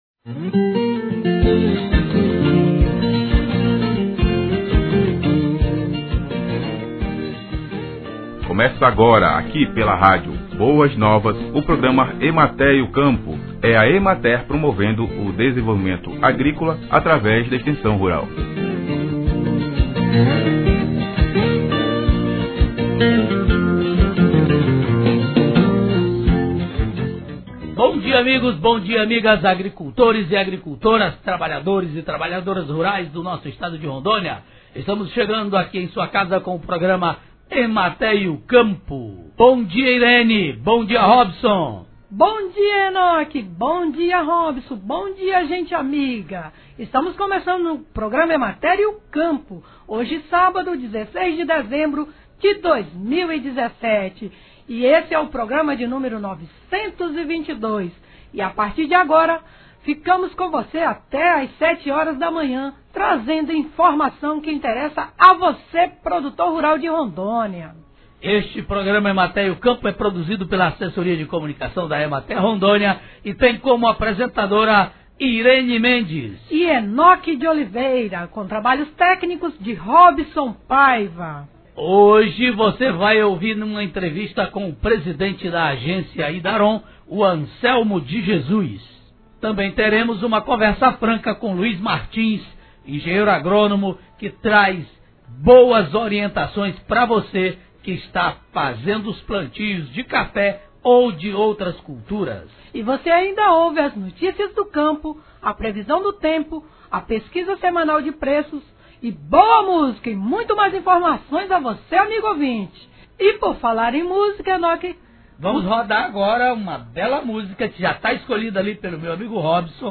Programa de Rádio